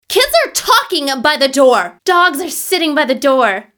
joyful.wav